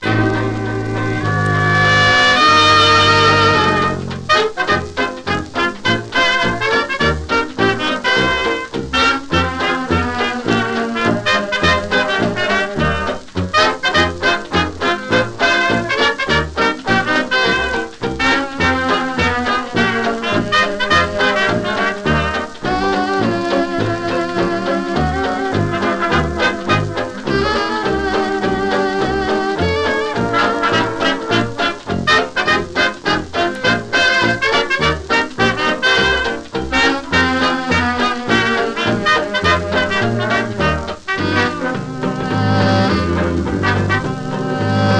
Jazz, Big Band　UK　12inchレコード　33rpm　Mono